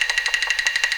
A#3 STICK0BL.wav